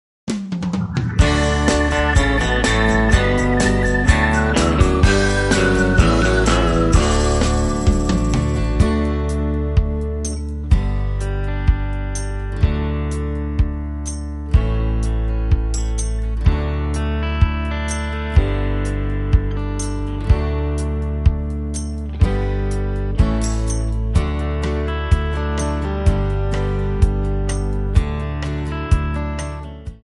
D
Backing track Karaoke
Country, 1990s